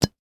Звук открытия крышки банки со сметаной